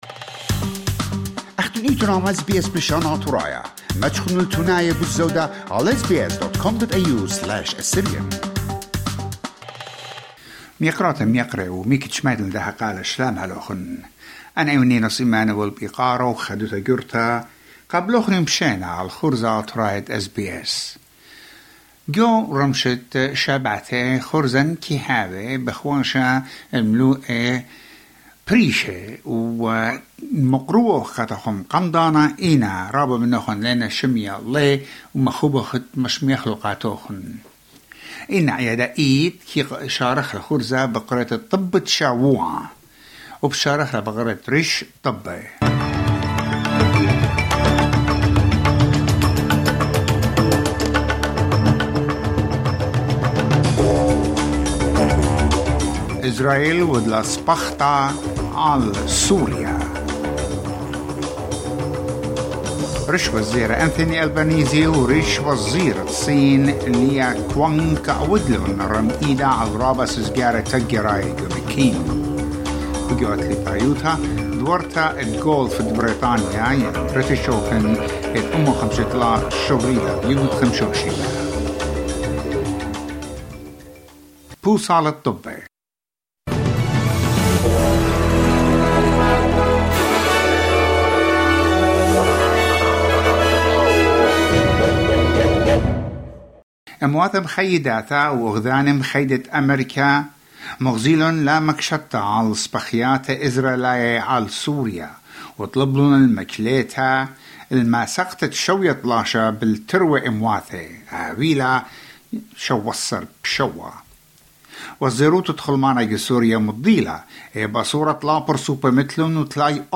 Assyrian weekly news wrap